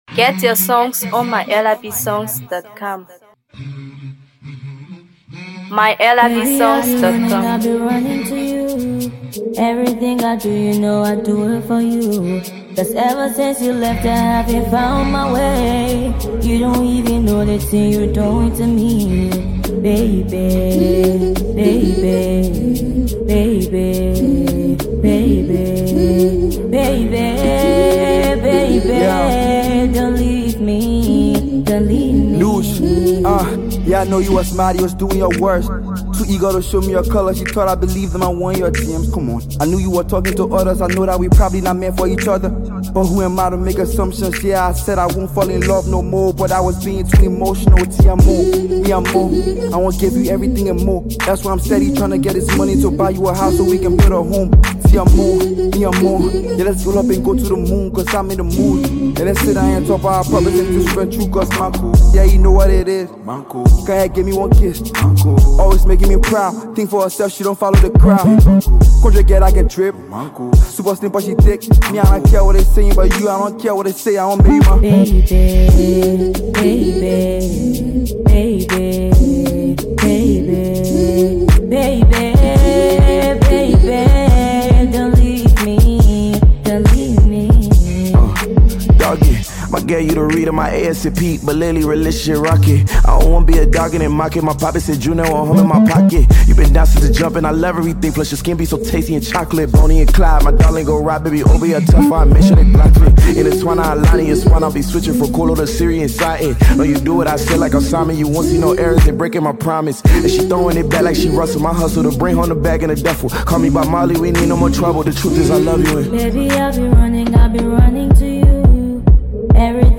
a more melodic approach
soulful voices